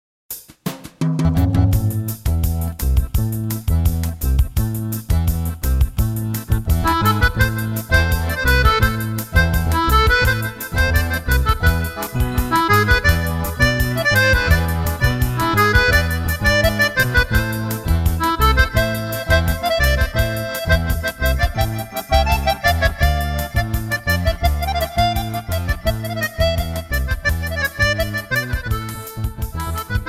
MIDI accordion with vocals